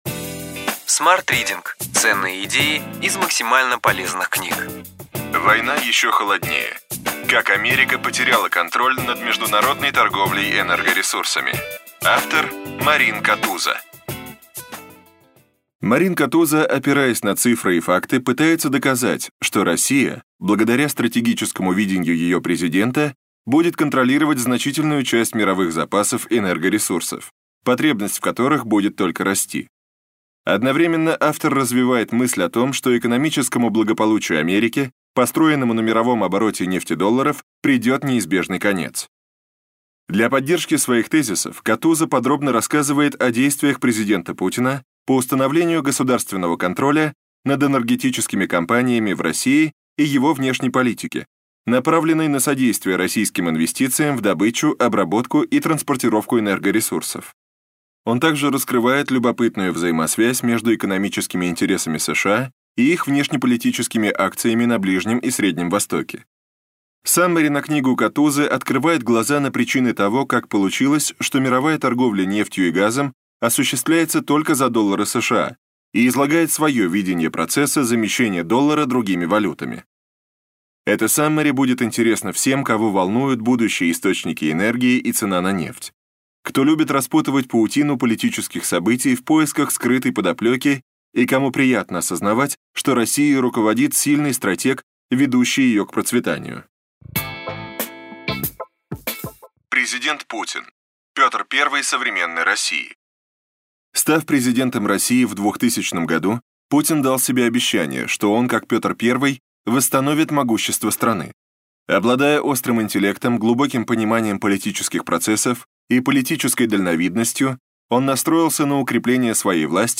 Аудиокнига Ключевые идеи книги: Война еще холоднее. Как Америка потеряла контроль над международной торговлей энергоресурсами.